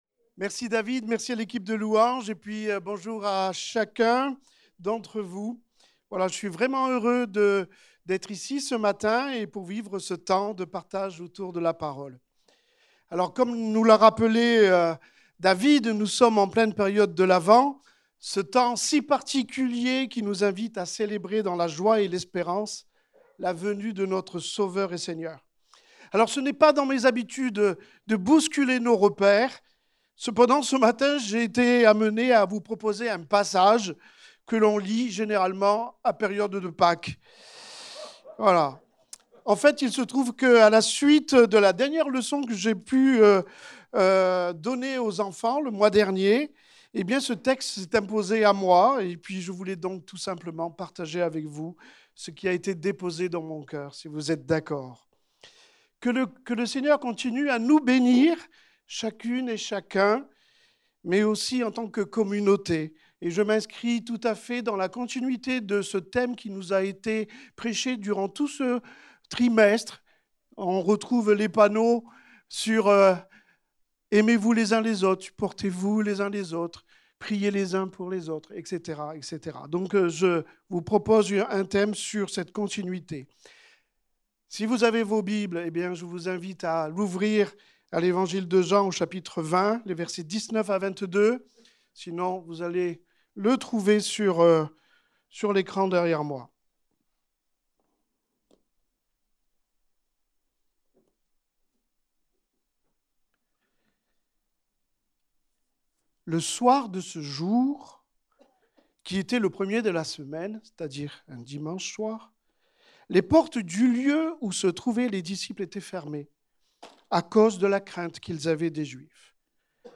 Culte du dimanche 07 décembre 2025, prédication